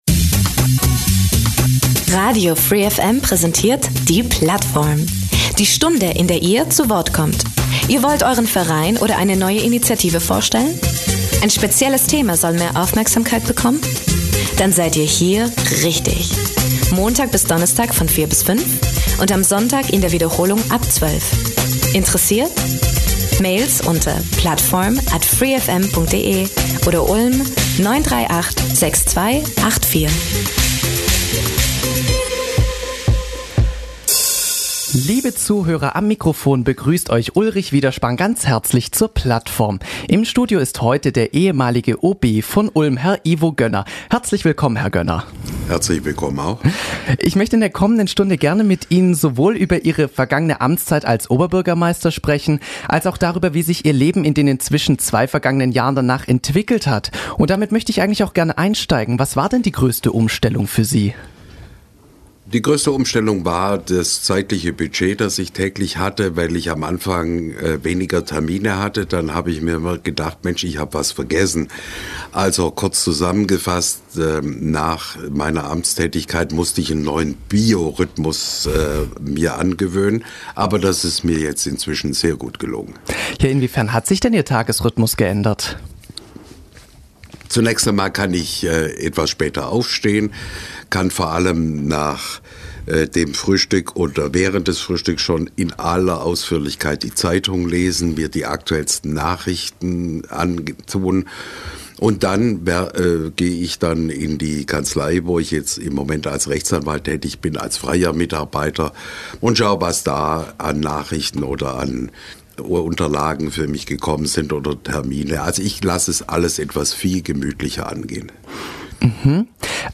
In unserer Plattformsendung am Dienstag, den 30.01.2018 um 16 Uhr haben wir mit Ivo Gönner über sein Leben jenseits der Stadtpolitik gesprochen.